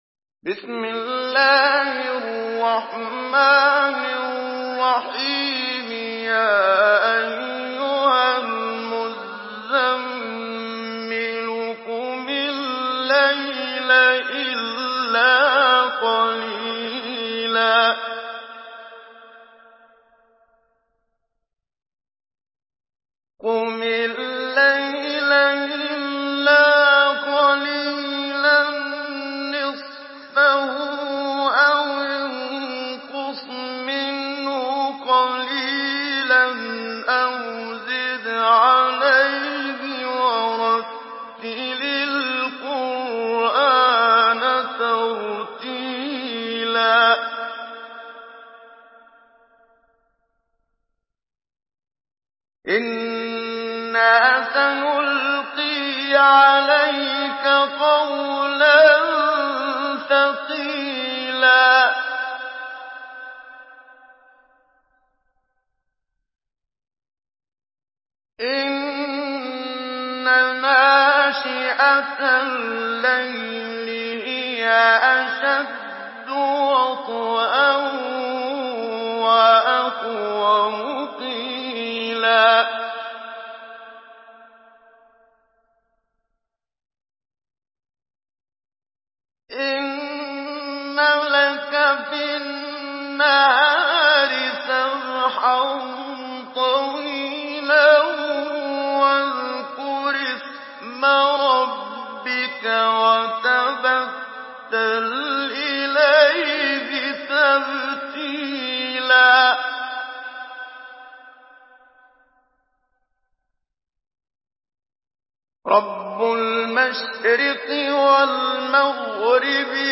Surah আল-মুযযাম্মিল MP3 by Muhammad Siddiq Minshawi Mujawwad in Hafs An Asim narration.